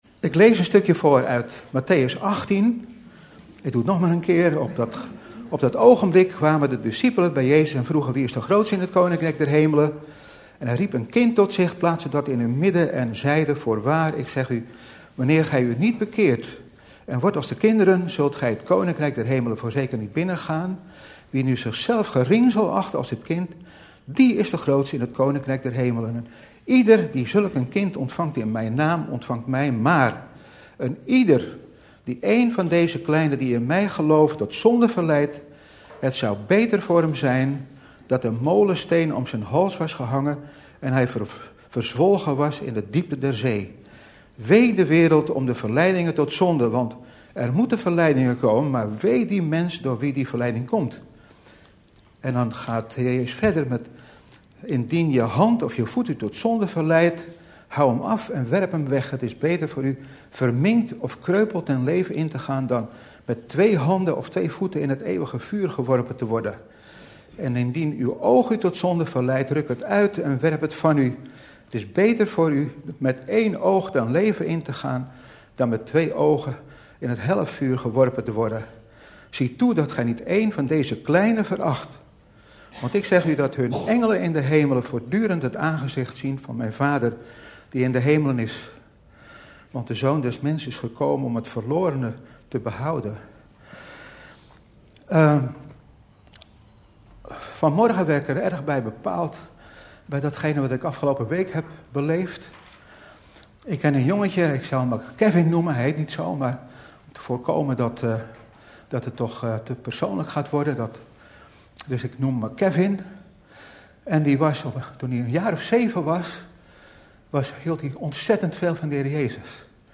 In de preek aangehaalde bijbelteksten (Statenvertaling)